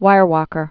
(wīrkər)